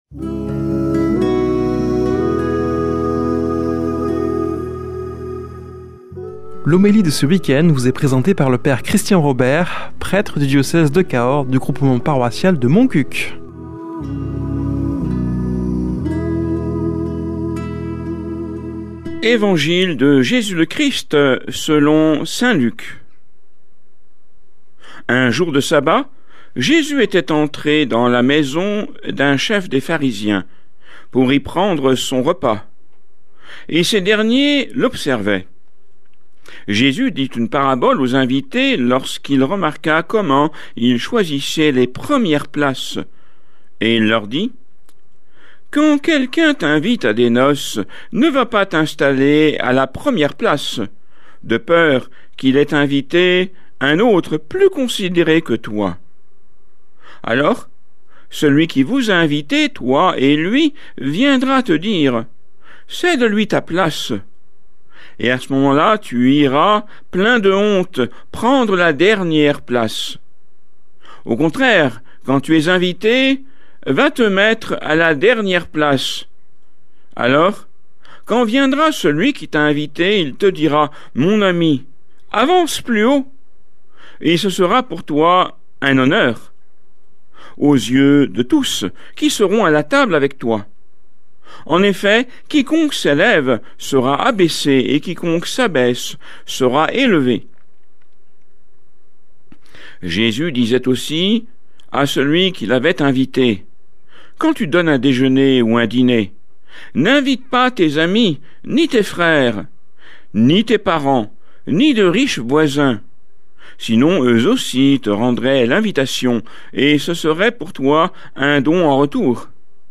Homélie du 30 août